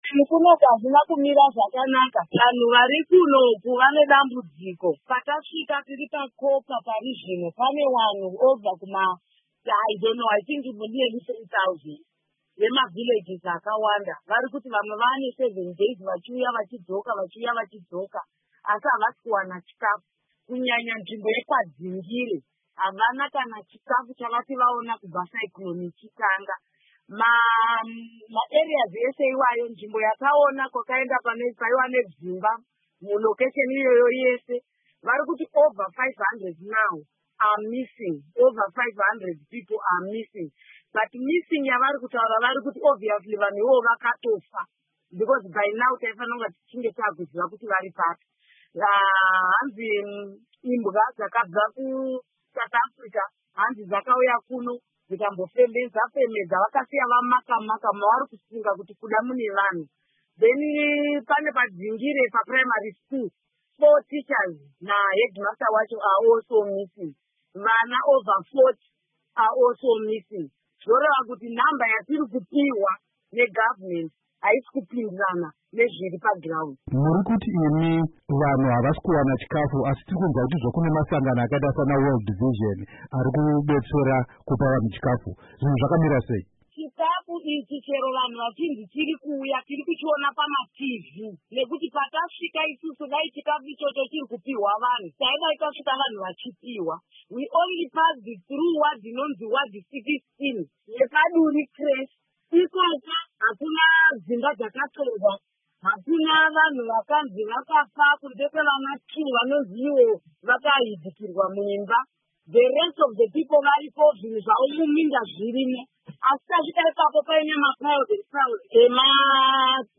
Hurukuro naAmai Lynette Karenyi